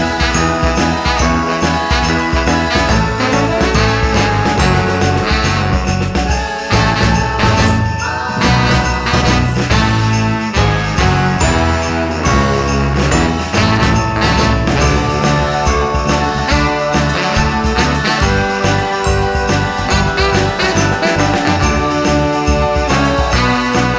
No Electric Guitar Christmas 4:10 Buy £1.50